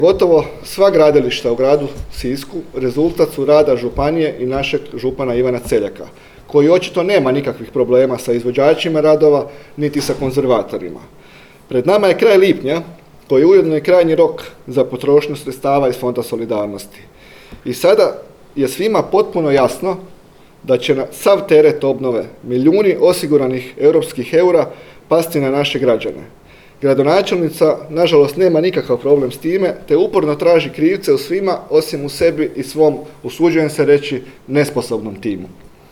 na danas održanoj tiskovnoj konferenciji